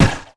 yeonsa_shot.wav